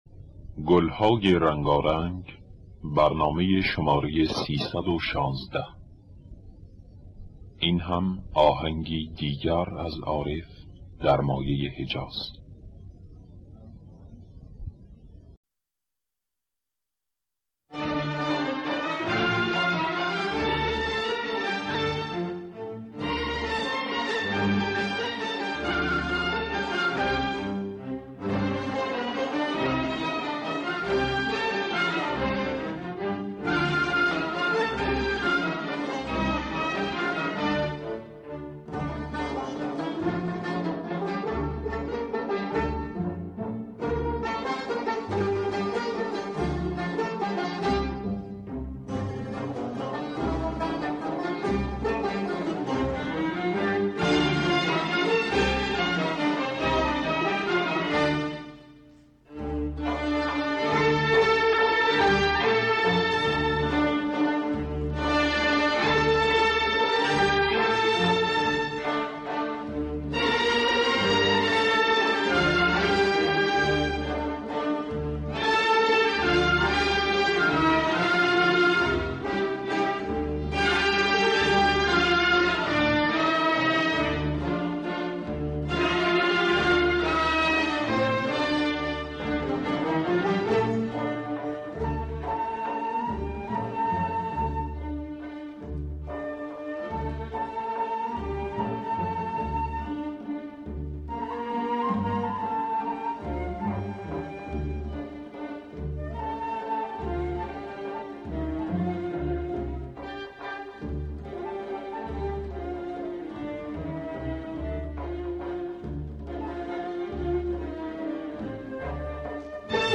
آرشیو کامل برنامه‌های رادیو ایران با کیفیت بالا.